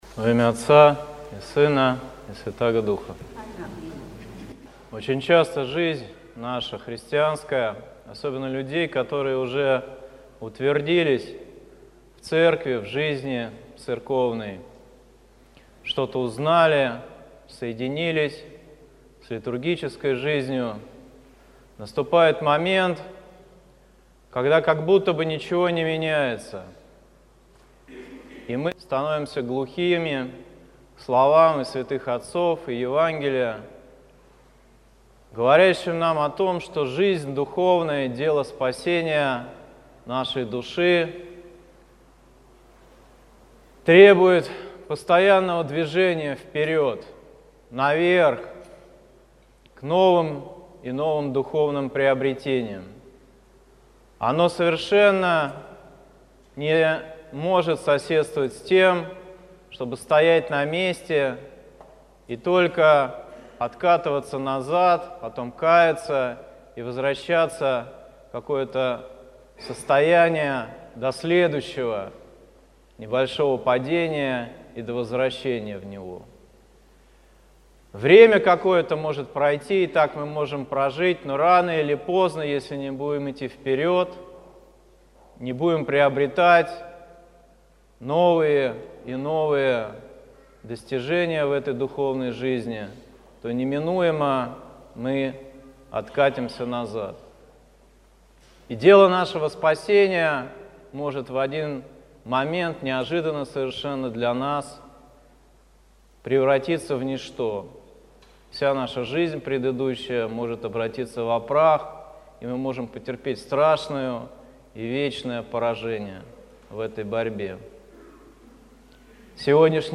Слово в Неделю 16-ю по Пятидесятнице
Псковская митрополия, Псково-Печерский монастырь